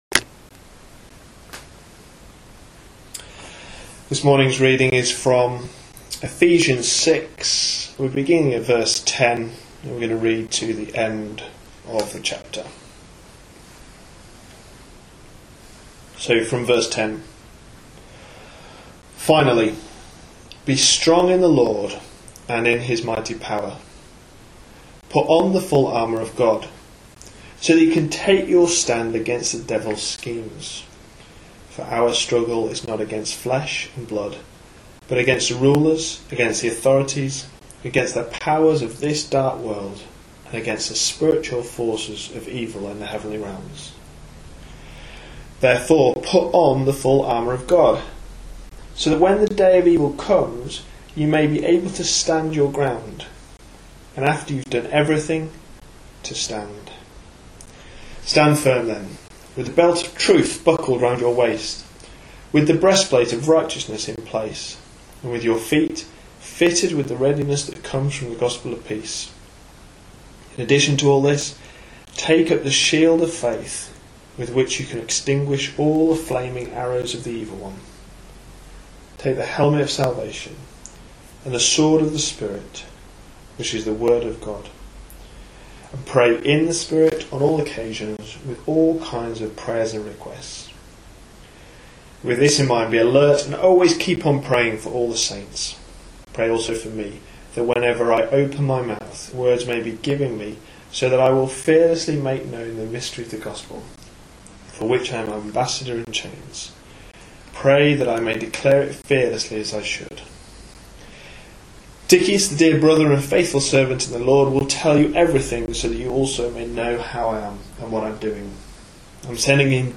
A sermon preached on 7th July, 2013, as part of our Ephesians series.